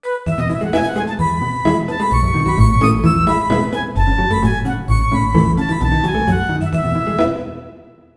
Tonalidad de Mi mayor. Ejemplo.
desenfadado
festivo
jovial
melodía
sintetizador
Sonidos: Música